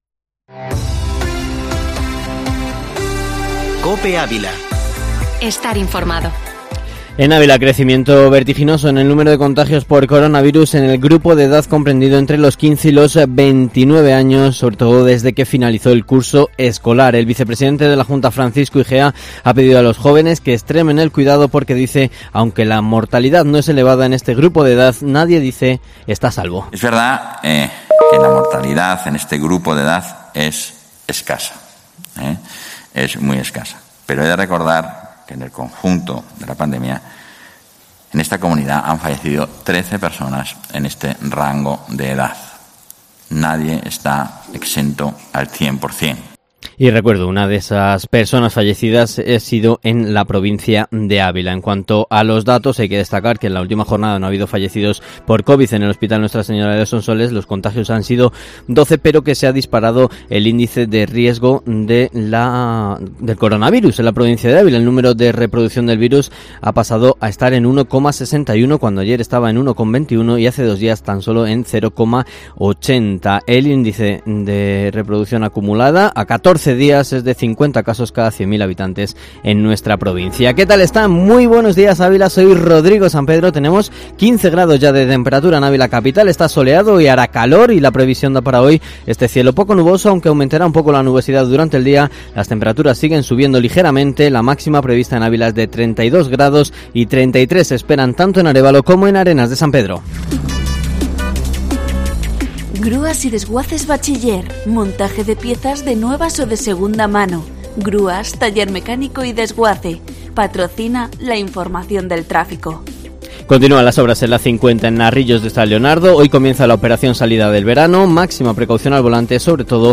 Informativo Matinal Herrera en COPE Ávila 2-julio